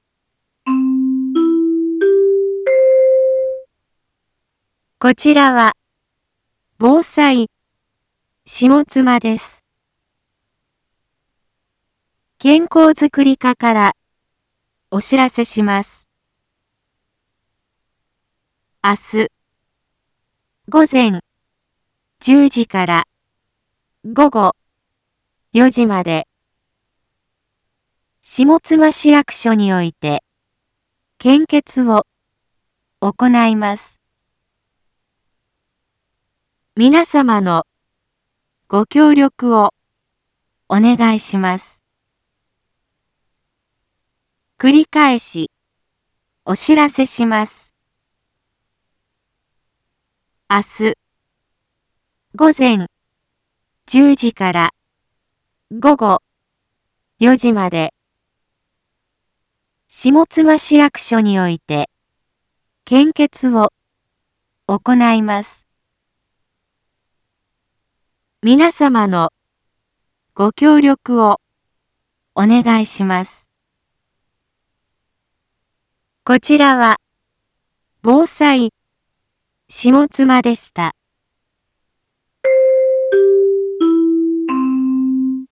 一般放送情報
Back Home 一般放送情報 音声放送 再生 一般放送情報 登録日時：2025-12-17 18:31:46 タイトル：献血のお知らせ（前日） インフォメーション：こちらは、ぼうさいしもつまです。